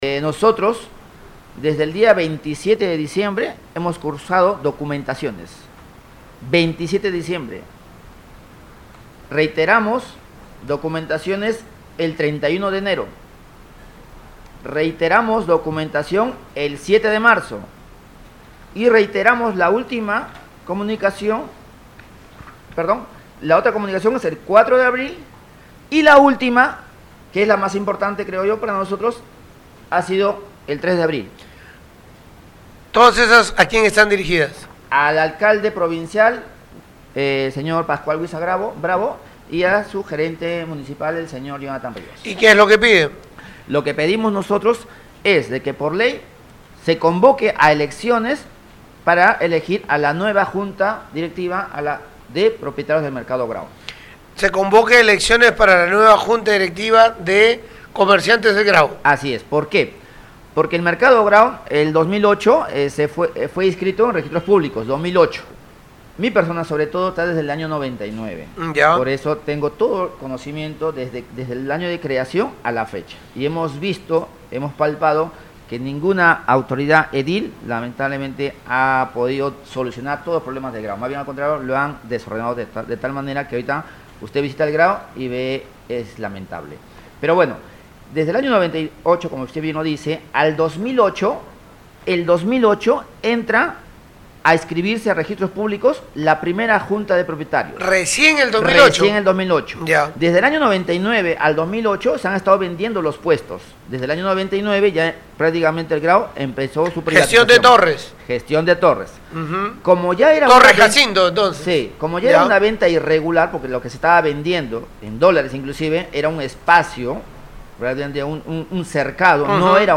La comitiva decidió este viernes 25 de abril exponer el caso en Radio Uno conminando a la MPT a brindar el balance económico respectivo